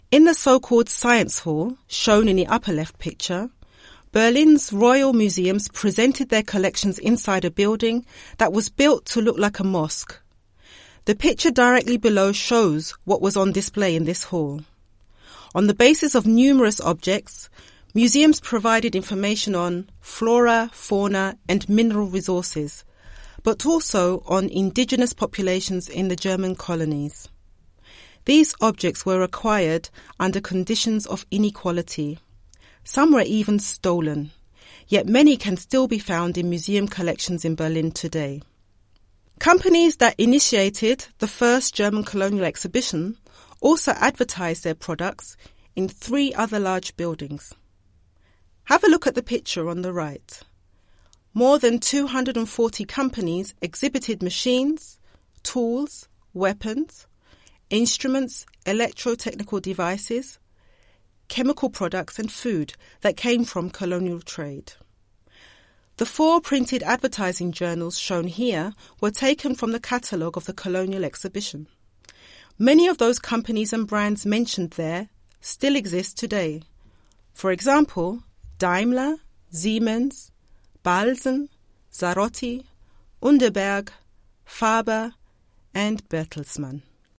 This English-language audioguide takes visitors through the “zurückGESCHAUT” exhibition at the Museum Treptow, which opened in 2017 and focuses on the “First German Colonial Exhibition” of 1896 in the Treptower Park.